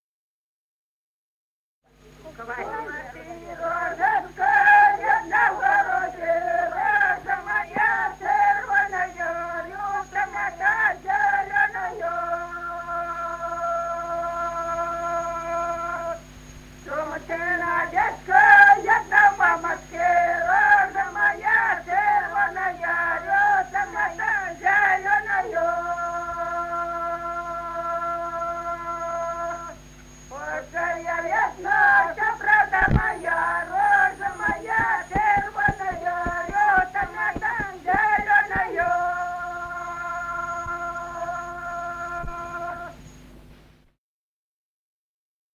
Народные песни Стародубского района «Что ты, розочка», новогодняя щедровная.
с. Курковичи.